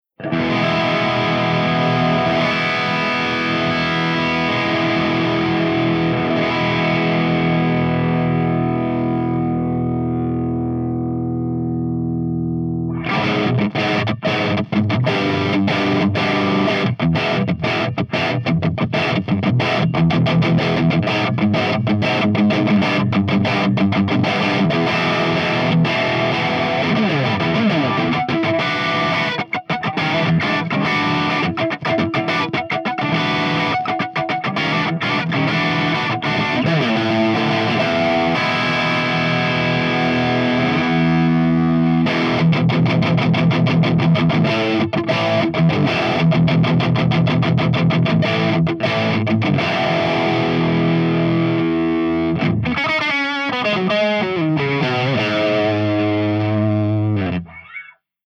147_MESA SINGLE RECTIFIER_CH2CRUNCH_V30_SC